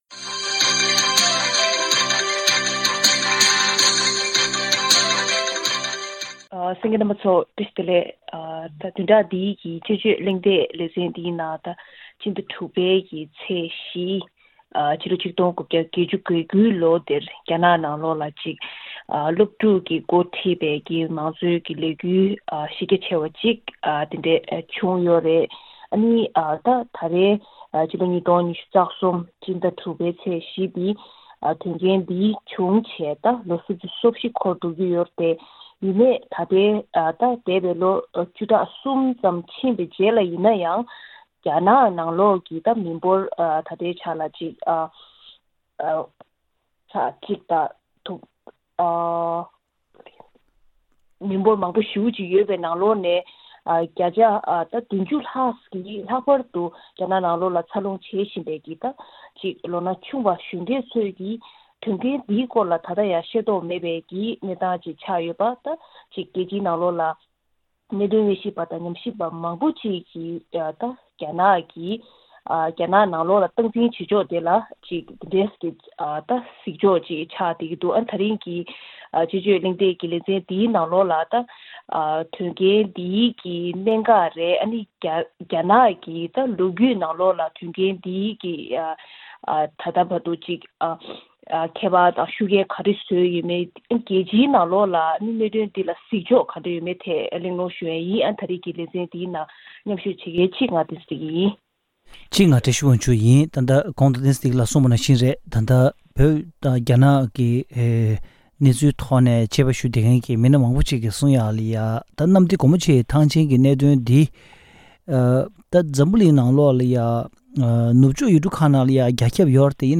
དཔྱད་གཞིའི་གླེང་མོལ་